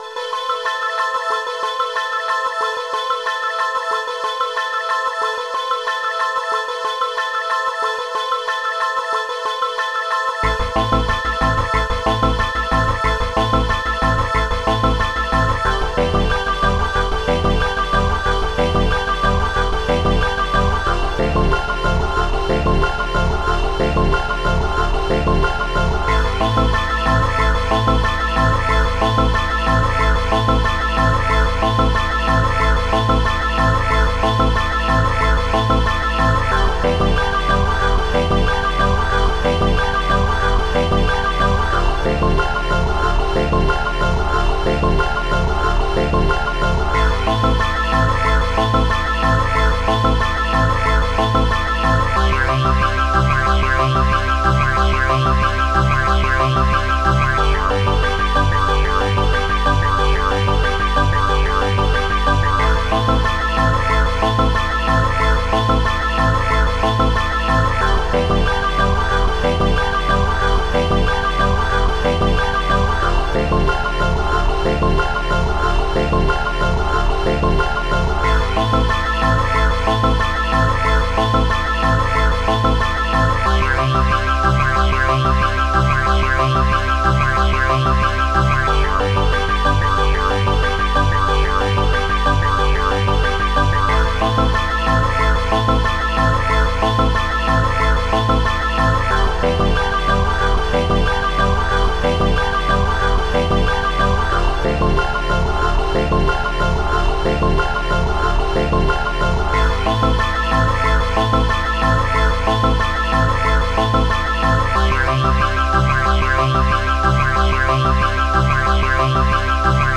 Short track suitable for a highscore input screen.
• Music has an ending (Doesn't loop)